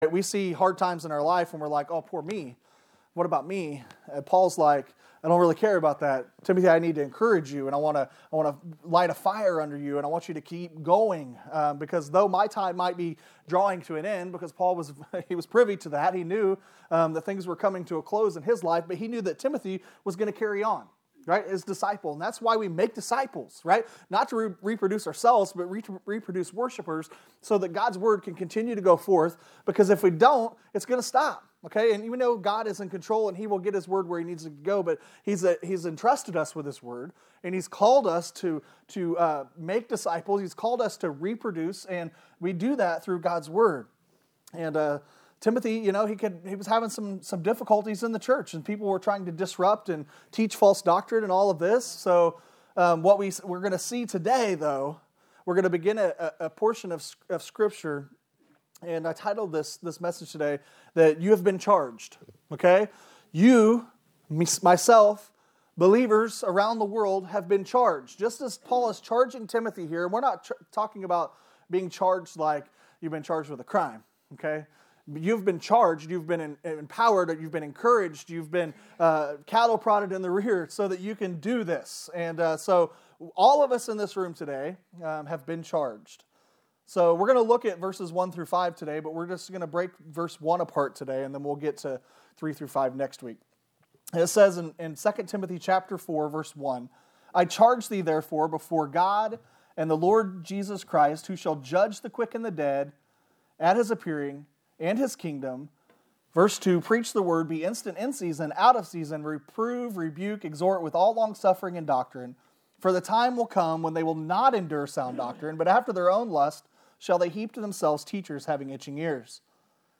You Have Been Charged - New Life Baptist Church of Clinton MO